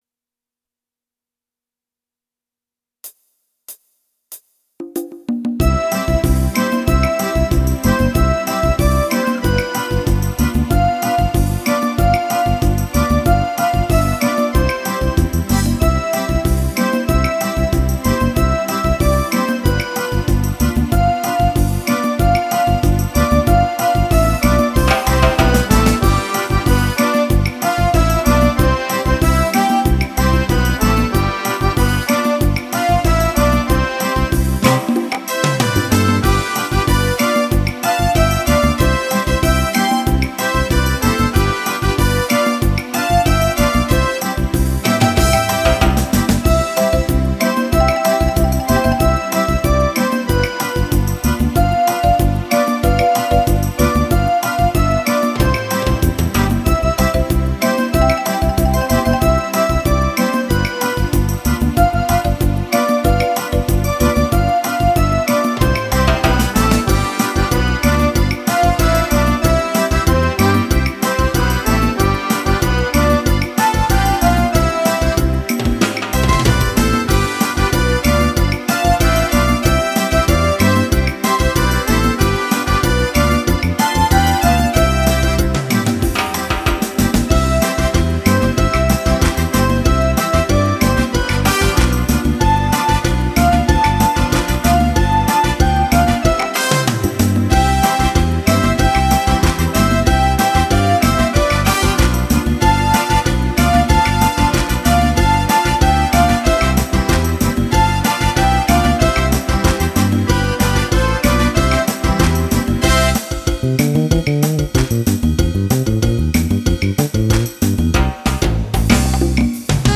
mambo, chacha, twist, poso doble, merengue, bachata